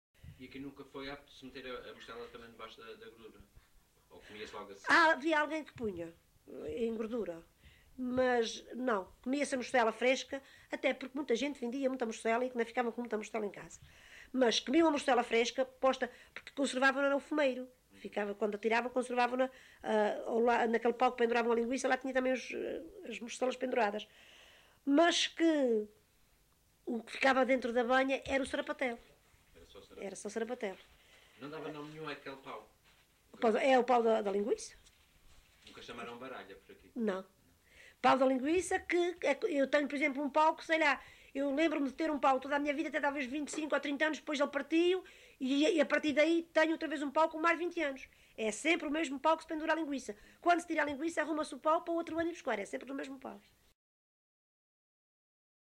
LocalidadeCedros (Horta, Horta)